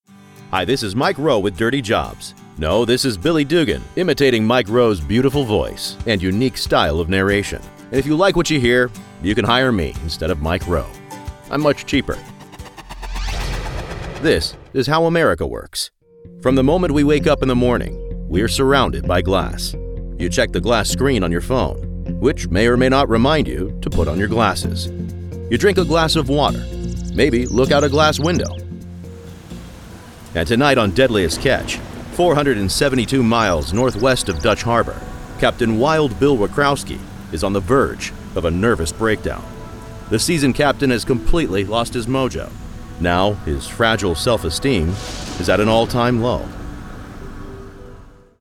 Male
Documentary
Mike Rowe Style
Words that describe my voice are conversational, trustworthy, authoritative.